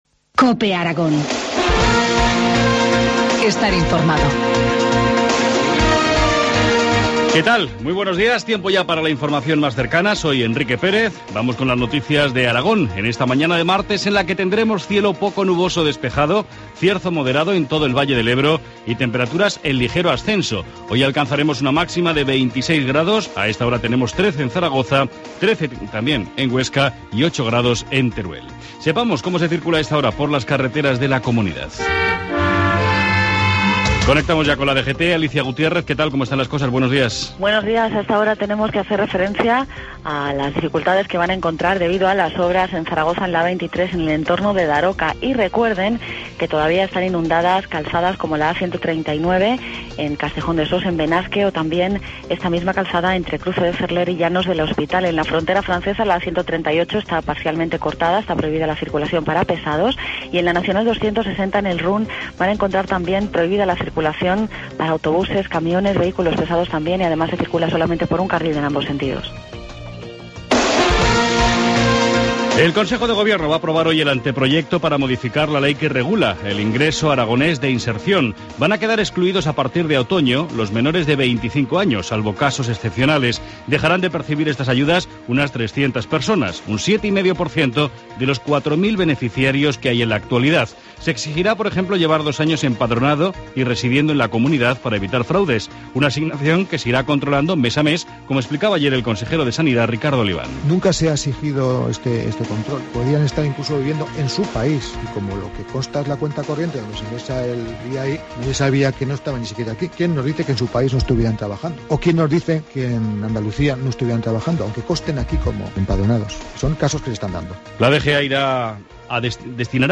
Informativo matinal, martes 25 de junio, 7.25 horas